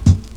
WU_BD_077.wav